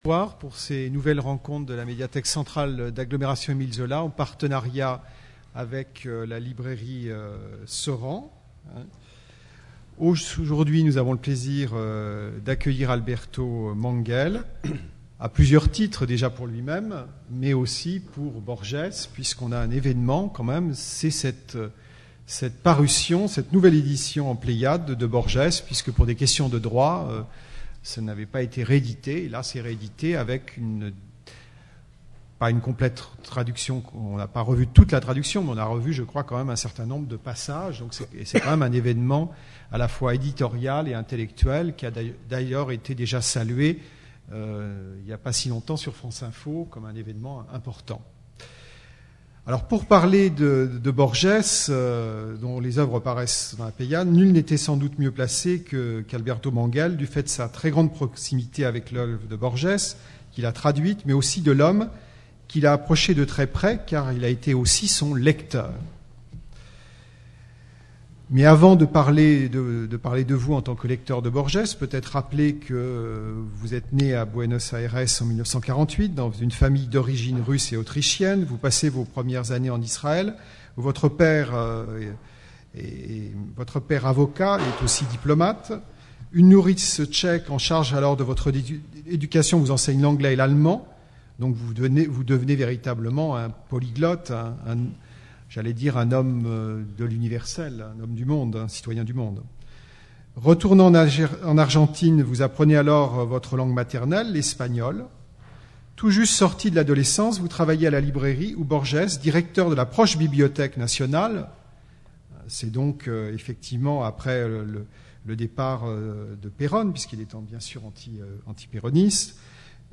Conférence d’Alberto Manguel
Rencontre littéraire